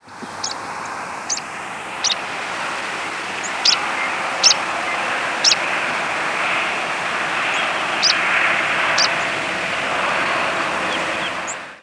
Sprague's Pipit diurnal flight calls
Bird in flight after being flushed.